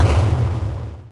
clear_3.ogg